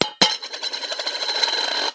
硬币掉落到锡中 " 硬币掉落4
描述：单枚硬币掉进一个罐子里